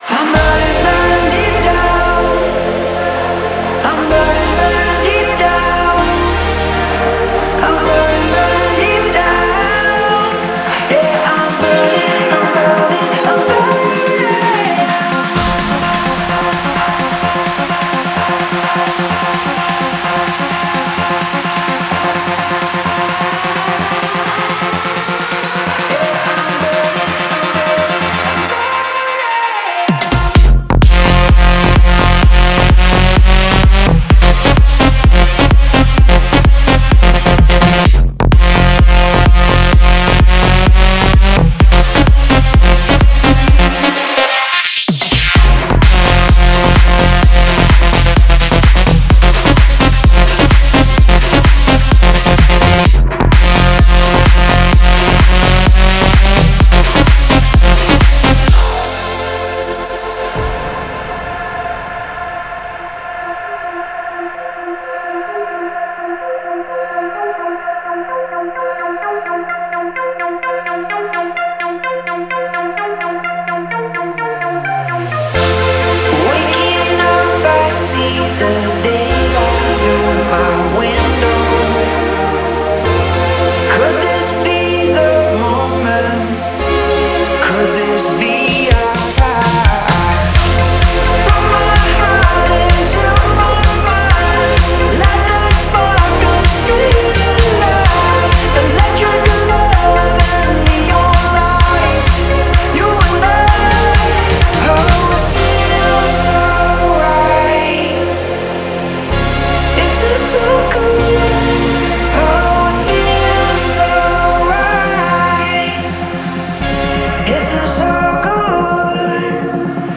это энергичная электронная танцевальная композиция